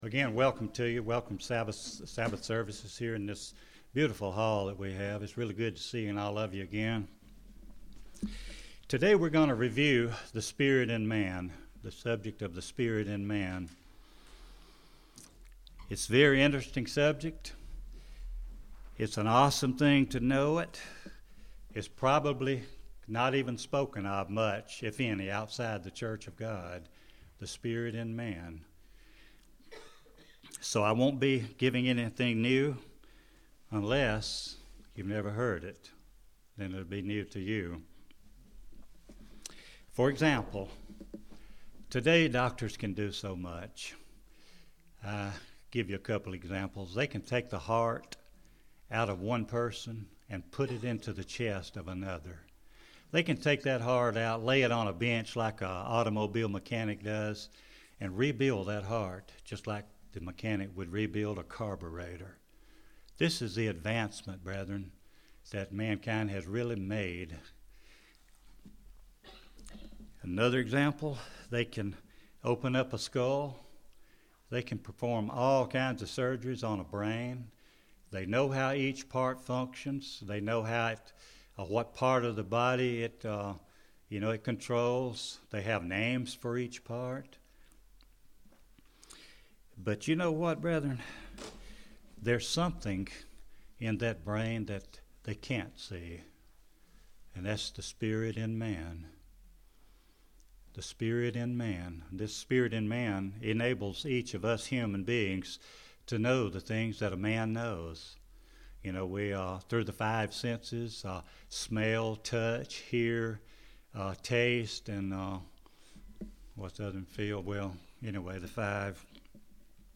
This sermon shows that the spirit in the mind of man which we were all born with, gives us the power of intellect through the five senses , enabling us to know physical things that we humans CAN know.
Given in Gadsden, AL